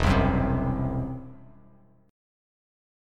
Esus2#5 chord